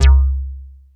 JUPIBASLC2-L.wav